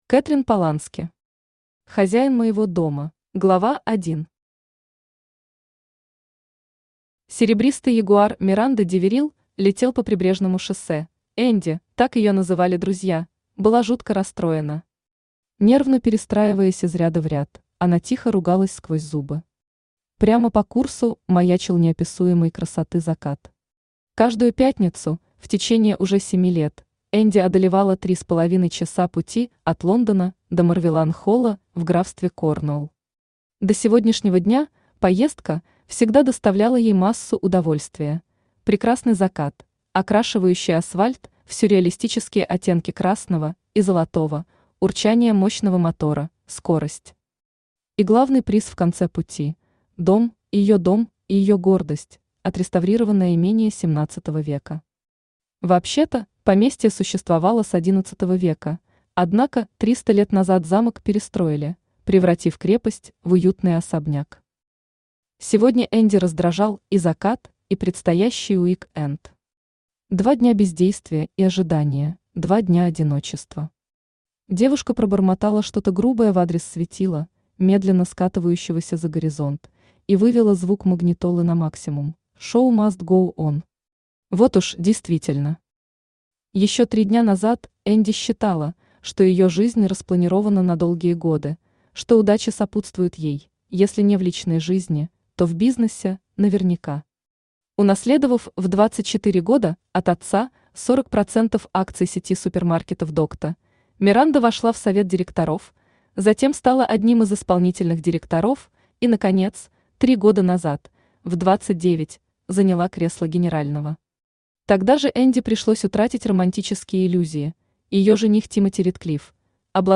Аудиокнига Хозяин моего дома | Библиотека аудиокниг